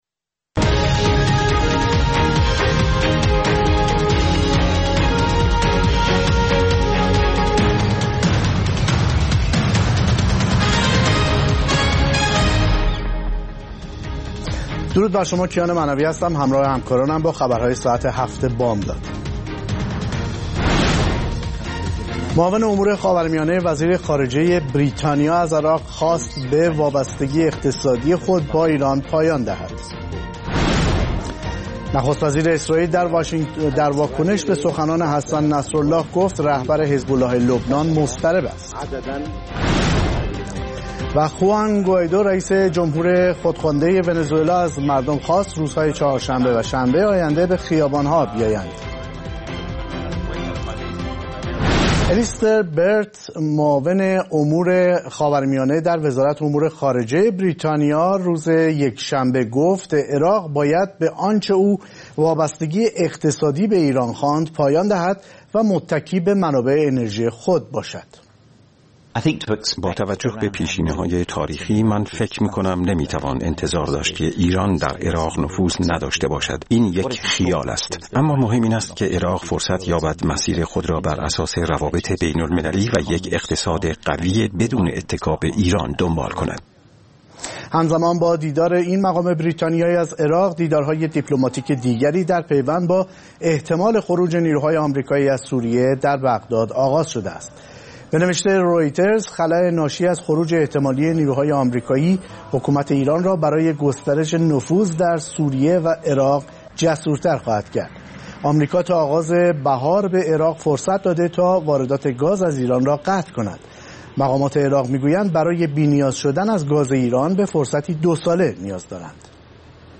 گزارشگران راديو فردا از سراسر جهان، با تازه‌ترين خبرها و گزارش‌ها، مجله‌ای رنگارنگ را برای شما تدارک می‌بينند. با مجله بامدادی راديو فردا، شما در آغاز روز خود، از آخرين رويدادها آگاه می‌شويد.